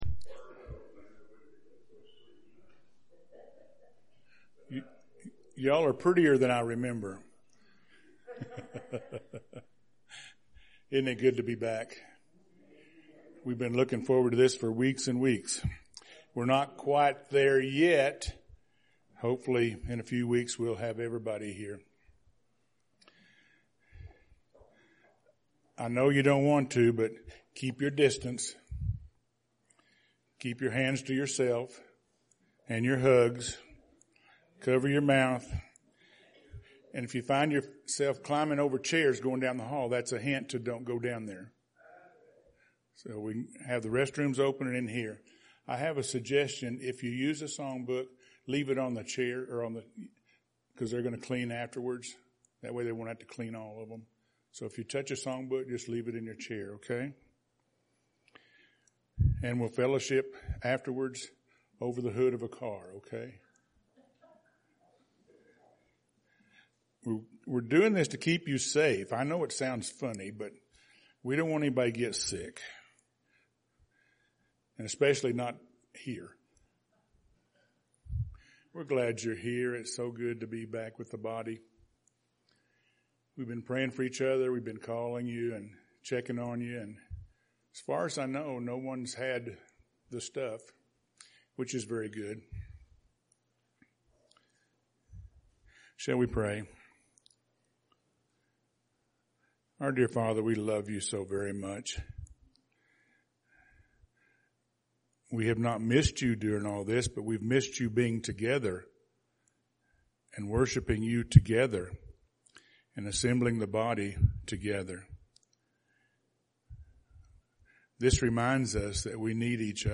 June 7th – Sermons